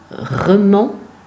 Romont (French pronunciation: [ʁɔmɔ̃] ; Arpitan: Remont [ʁəˈmɔ̃]